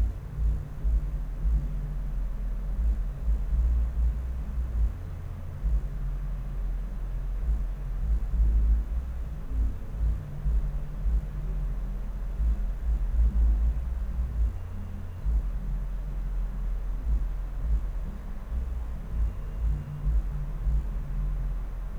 "Musik" aus den Wänden
Schallbrücken